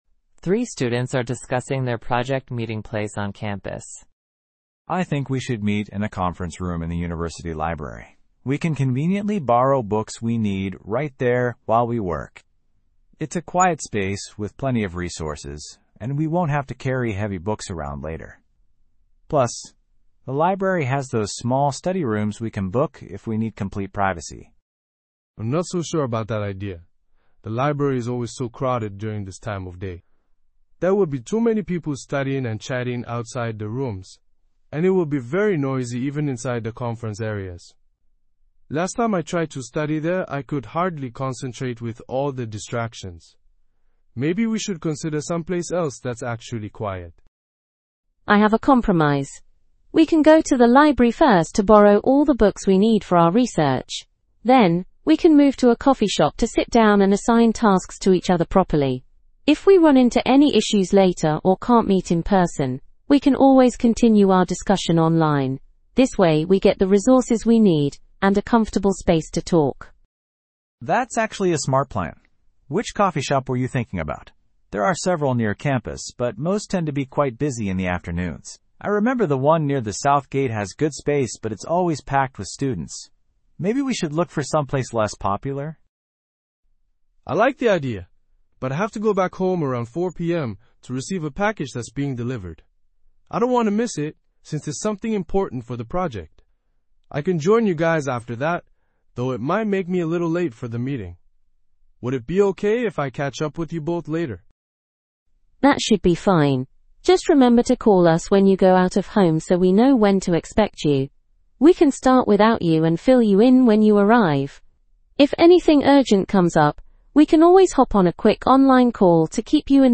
PTE Summarize Group Discussion – Meeting